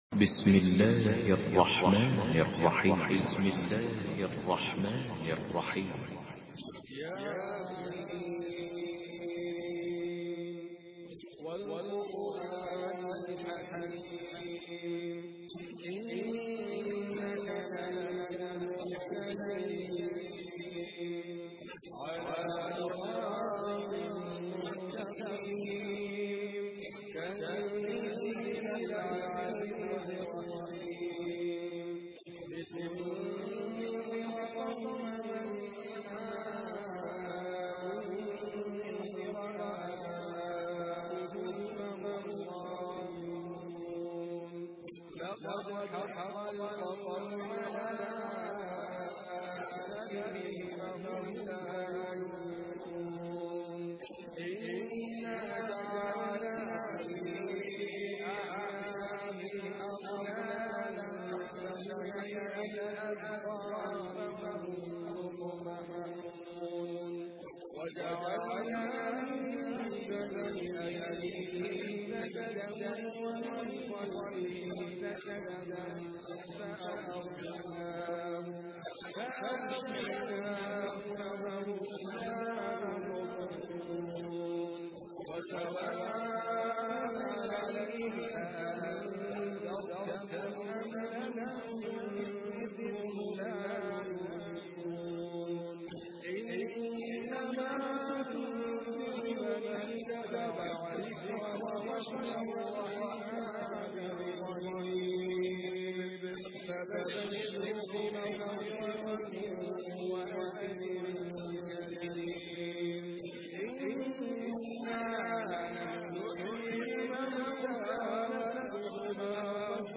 Surah ইয়াসীন MP3 by Idriss Abkar in Hafs An Asim narration.
Murattal Hafs An Asim